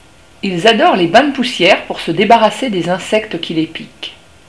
Le moineau
Le cri du moineau